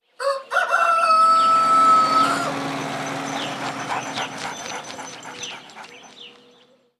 Les sons vont au-delà des simples cris des animaux. Ils restituent une ambiance.